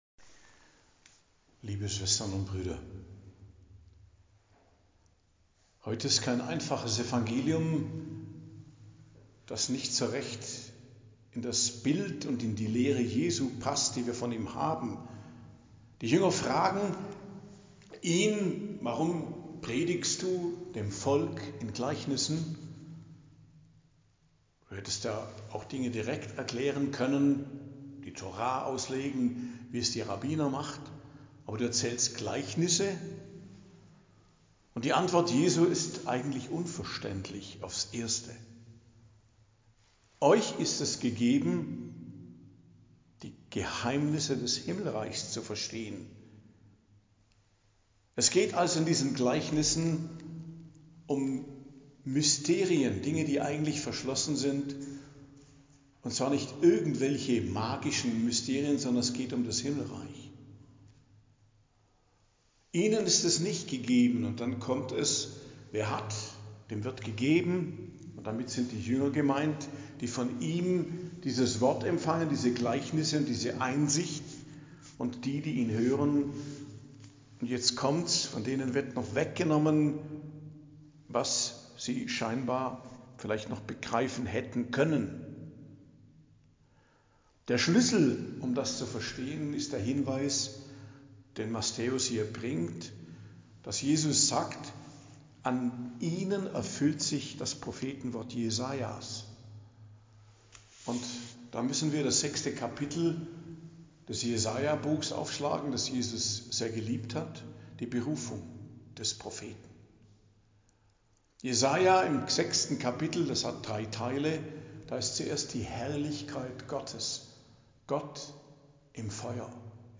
Predigt am Donnerstag der 16. Woche i.J., 24.07.2025